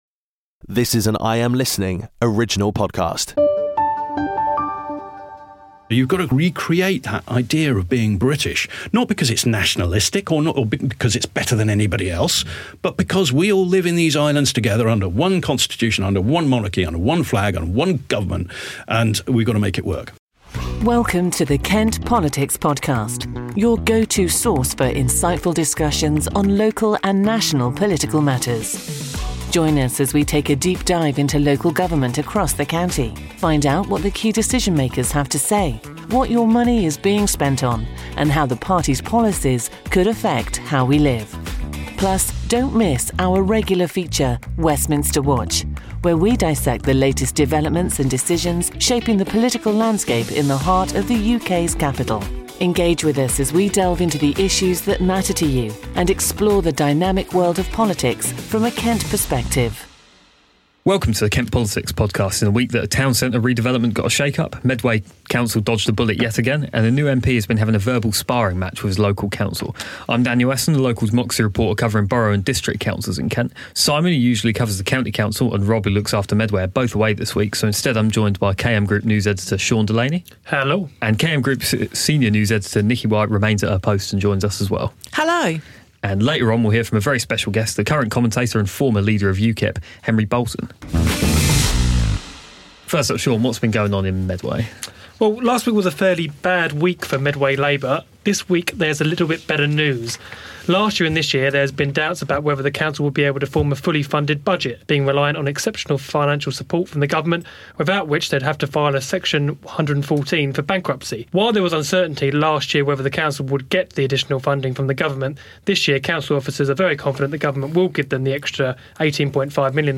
Don't miss our exclusive interview with Henry Bolton, former UKIP leader, offering insights from his diverse career.